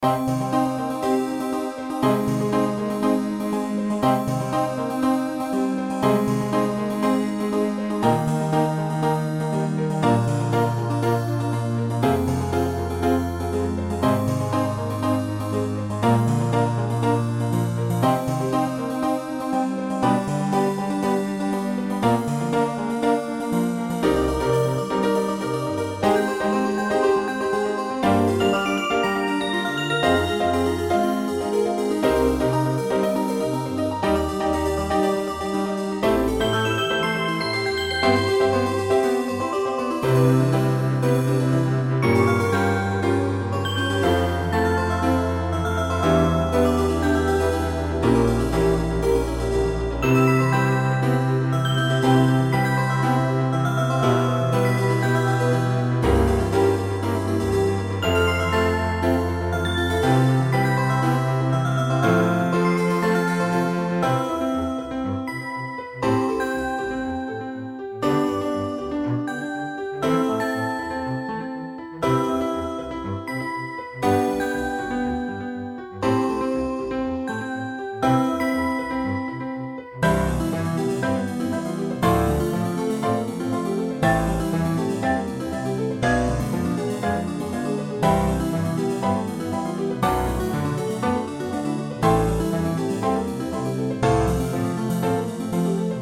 Fx5(ブライトネス)、ピアノ、コントラバス